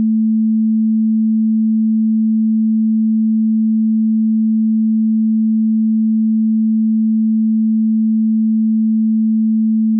front_sin220_FuMa.wav